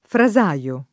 frasaio [ fra @#L o ] s. m.; pl. ‑sai